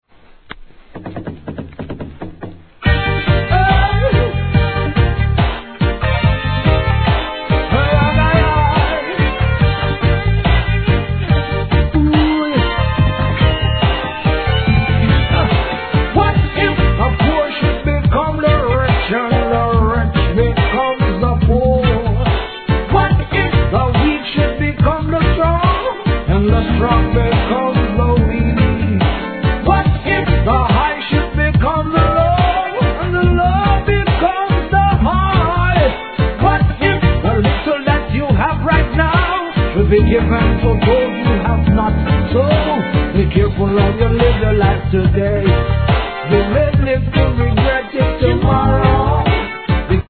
REGGAE
人生の助言となる力強いヴォーカルでのメッセージ!